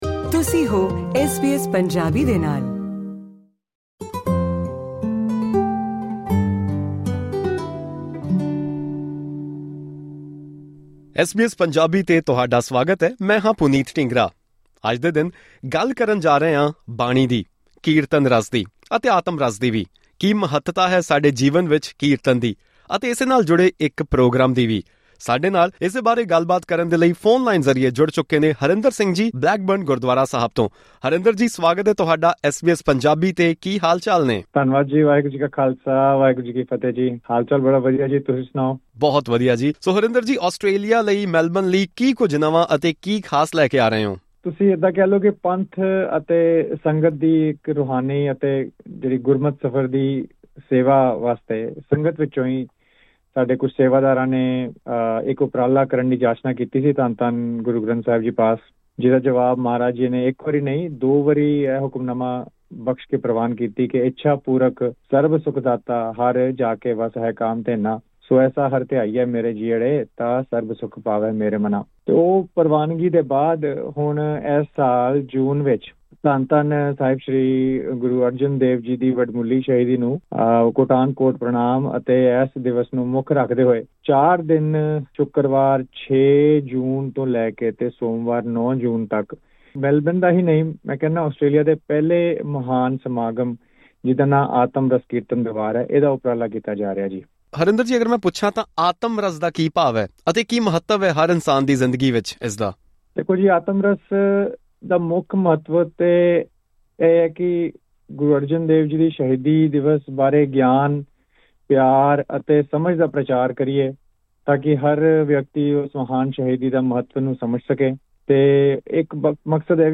ਪੂਰੀ ਇੰਟਰਵਿਊ ਲਈ ਸੁਣੋ ਸਾਡਾ ਇਹ ਪੌਡਕਾਸਟ...